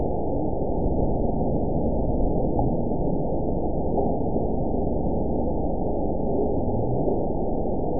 event 920372 date 03/20/24 time 16:53:30 GMT (1 year, 1 month ago) score 8.87 location TSS-AB01 detected by nrw target species NRW annotations +NRW Spectrogram: Frequency (kHz) vs. Time (s) audio not available .wav